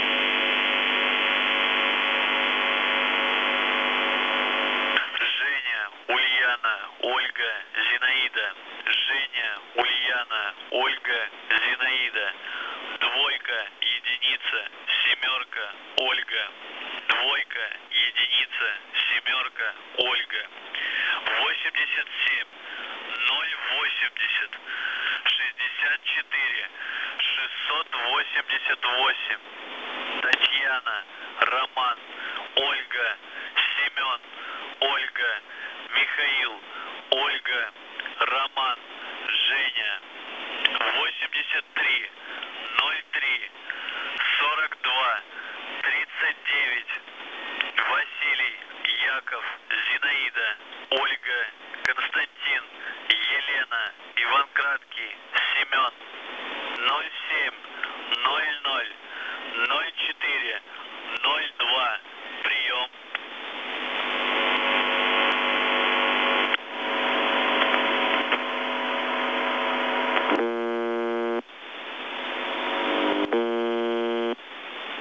Mode: USB